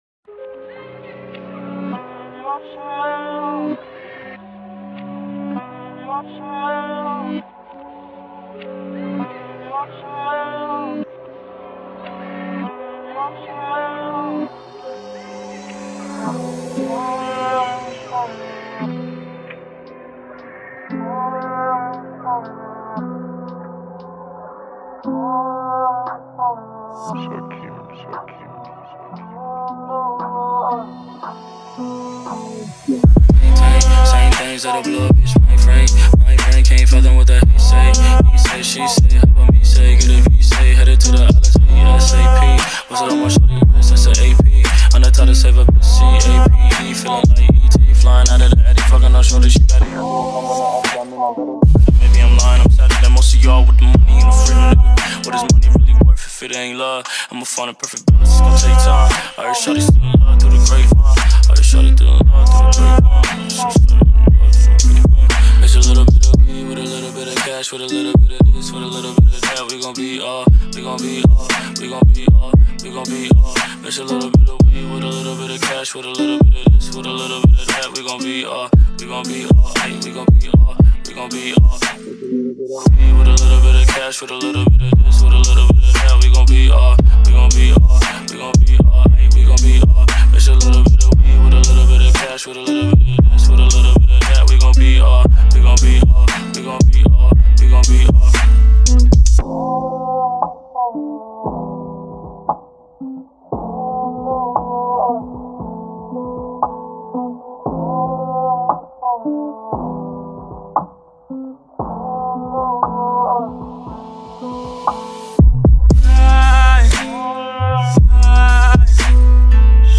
ژانر: رپ & آر اند بی & پاپ